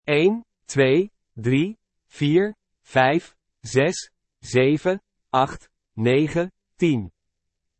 The numbers from 1 to 10 are pronounced: